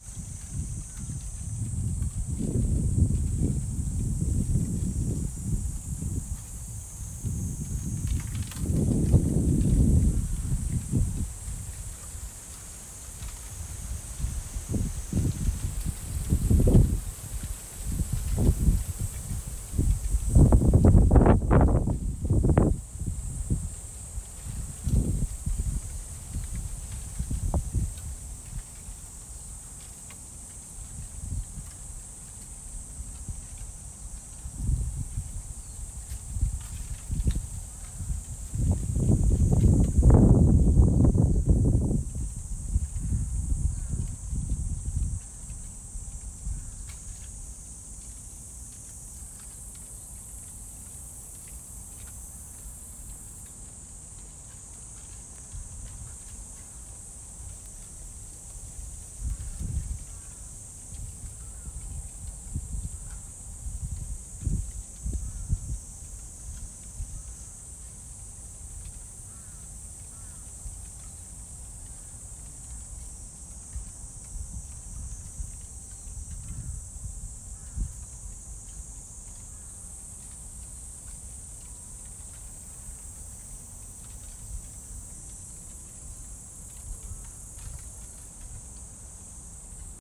環境音 竹林の揺れる音 Bamboo trees with wind